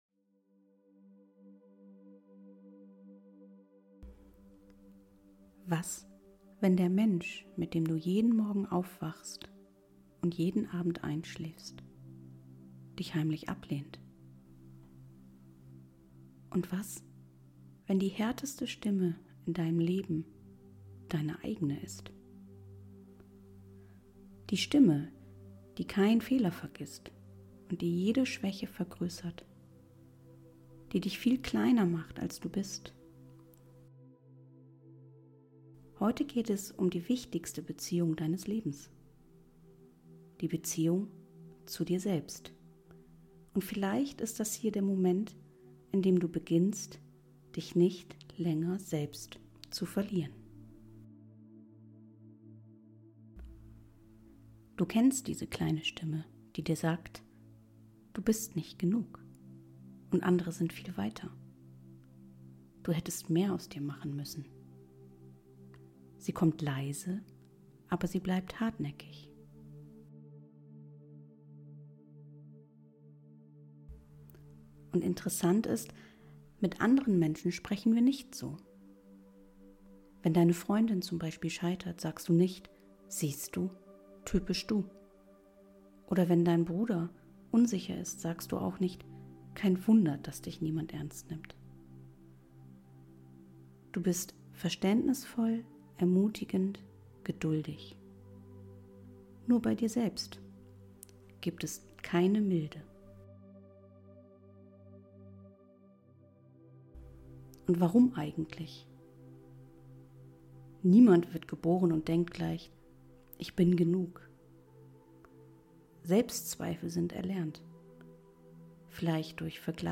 In dieser tiefgehenden und sehr stillen Folge geht es um die Beziehung, die dein ganzes Leben bestimmt – die zu dir selbst. Über den inneren Kritiker.